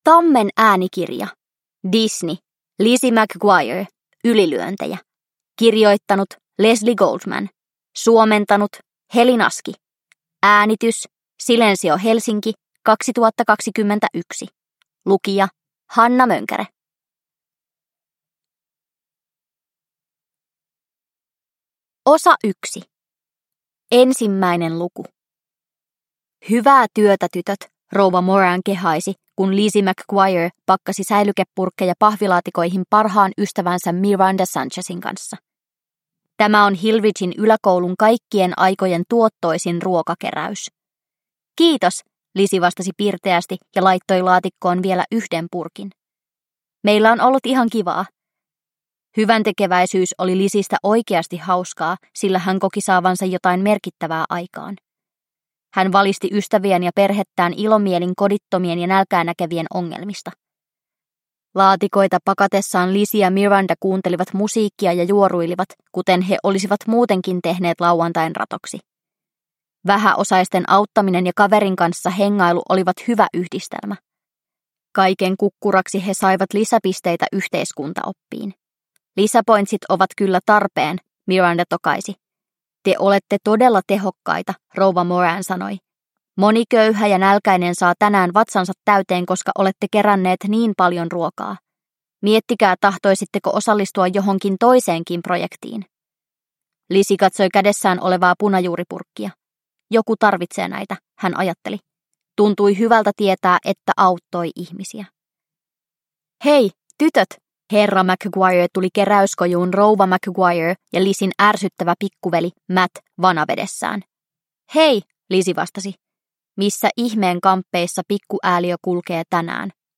Lizzie McGuire. Ylilyöntejä (ljudbok) av Disney